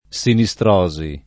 [ S ini S tr 0@ i ]